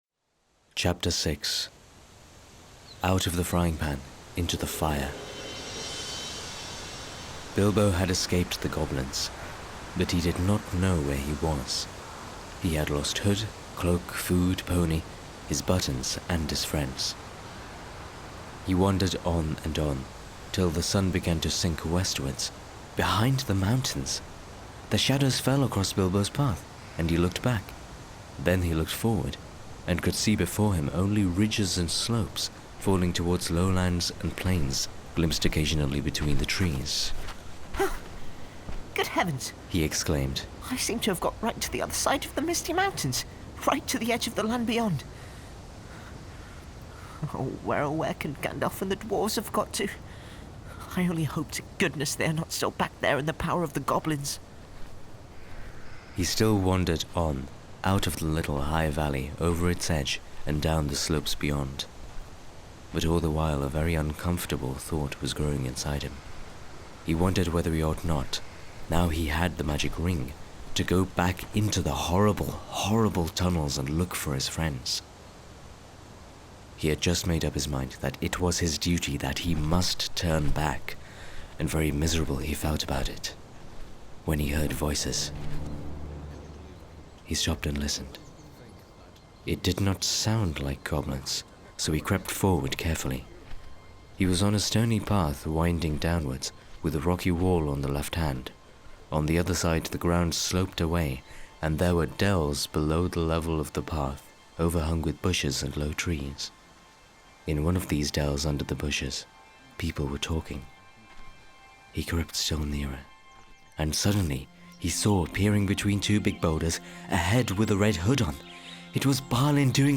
Lord Of The Rings And Hobbit Audiobooks (J.R.R. Tolkien)!